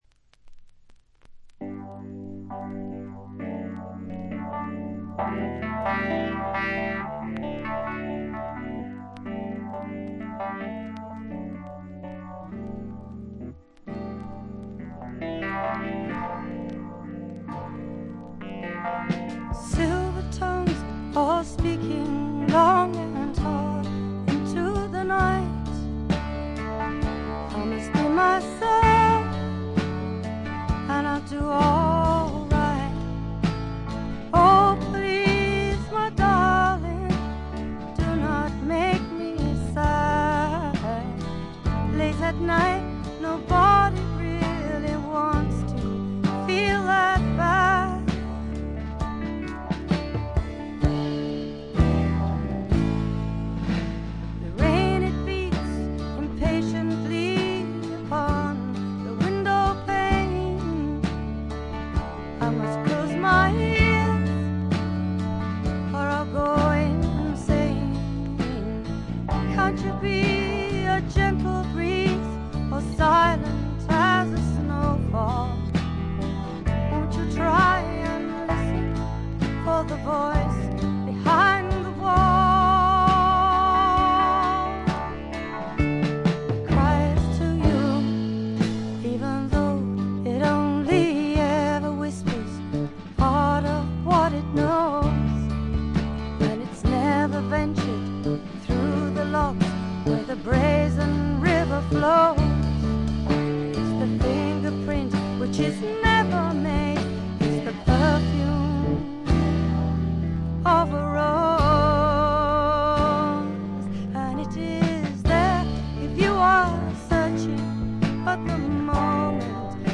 チリプチ多めですが、大きなノイズはありません。
試聴曲は現品からの取り込み音源です。
Recorded and mixed at Olympic Sound Studios, London